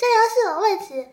Techmino/media/effect/chiptune/error.ogg at 59cd9347c2e930d93b419b9637e1822f2d5d8718
error.ogg